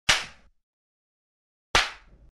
Звуки ударов, пощечин